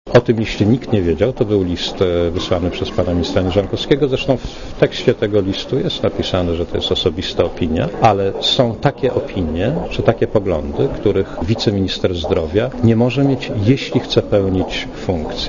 Posłuchaj komentarza Marka Balickiego